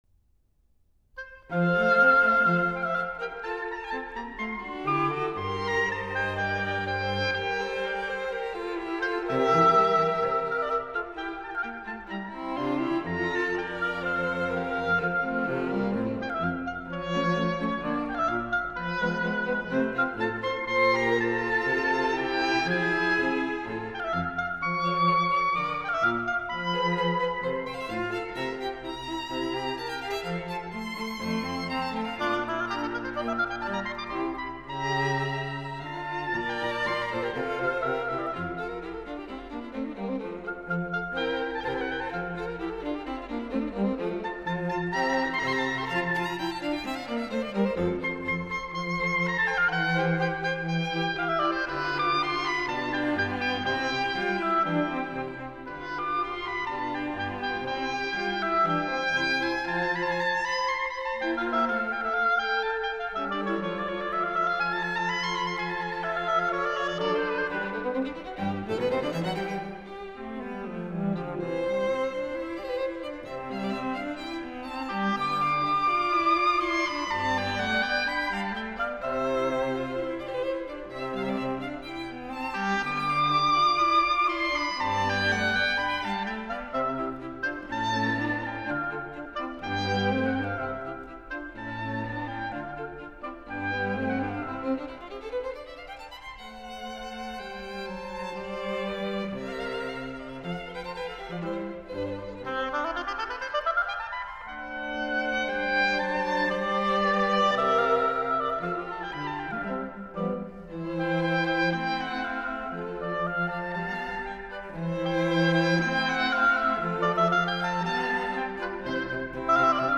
Mozart Cello Quartet.mp3 (7.91 Mb)